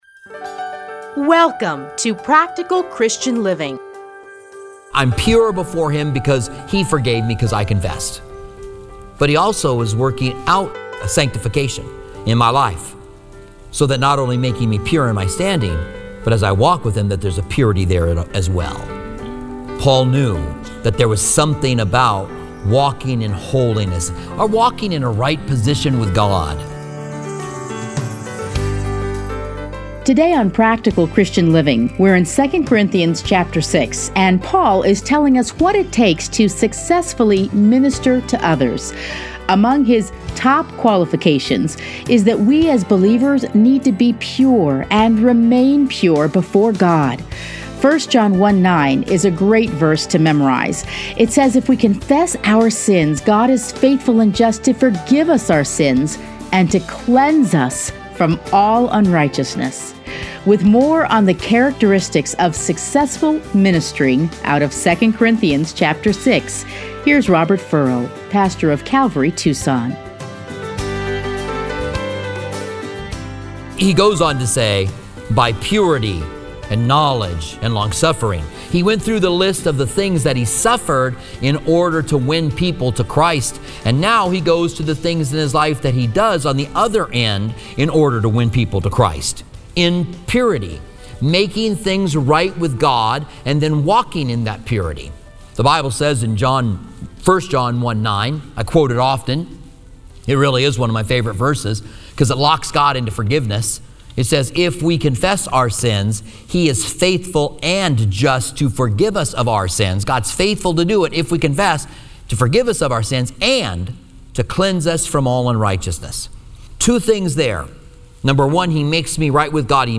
Listen here to a teaching from 2 Corinthians.